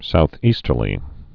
(south-ēstər-lē, sou-ē-)